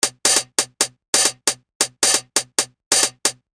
Skat Hats 135bpm.wav